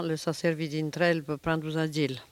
Langue Maraîchin
Patois - archive